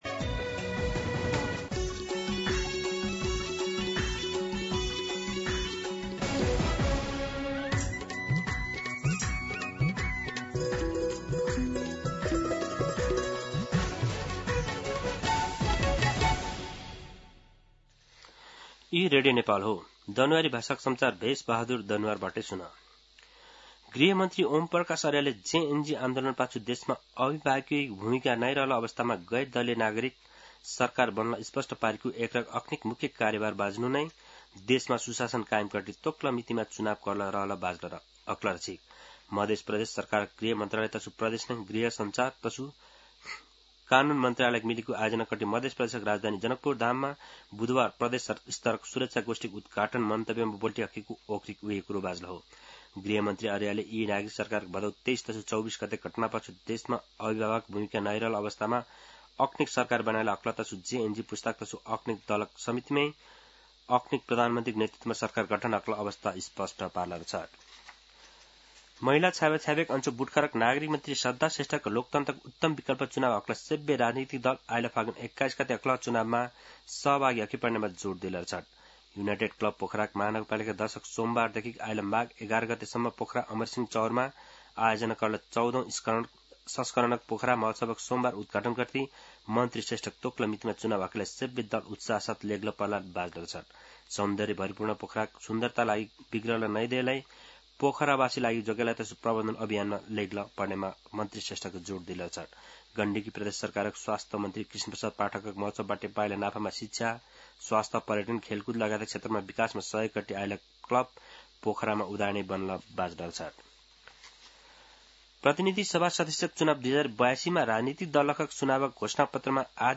दनुवार भाषामा समाचार : ३० पुष , २०८२
Danuwar-News-9-30.mp3